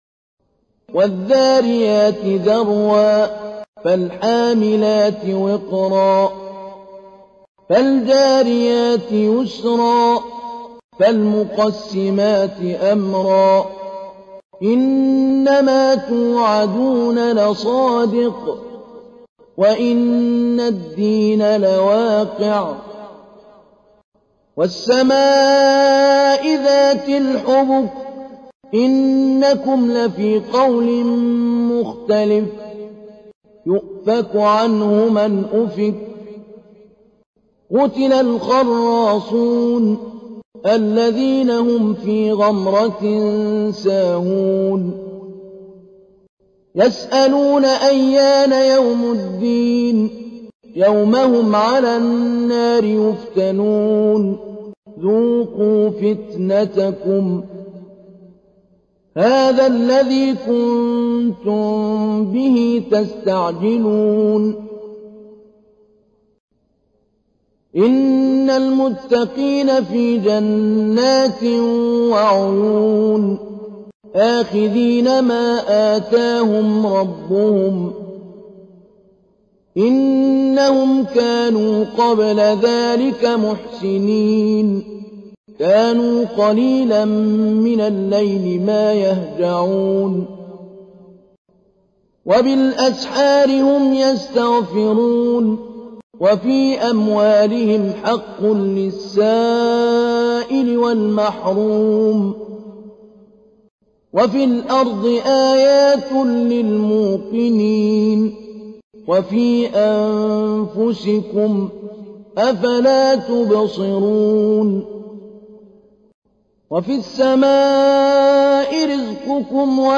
تحميل : 51. سورة الذاريات / القارئ محمود علي البنا / القرآن الكريم / موقع يا حسين